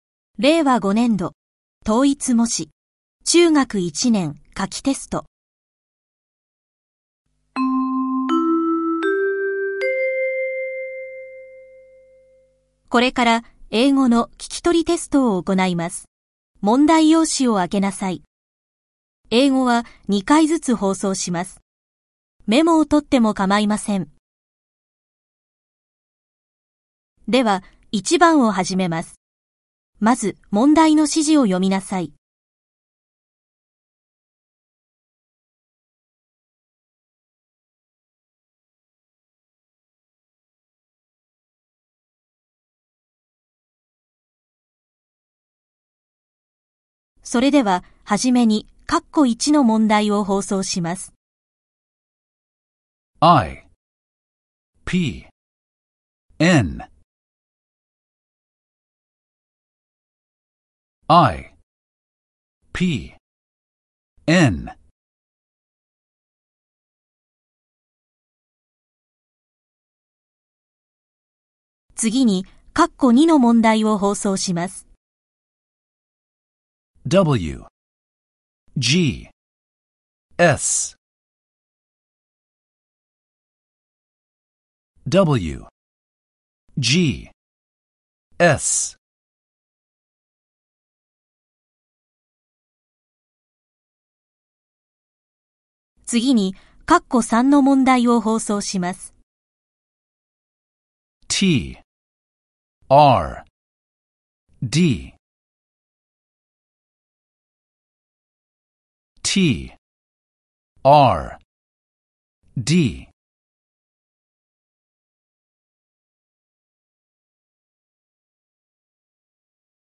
聞き取りテストサンプル
demo-listening.mp3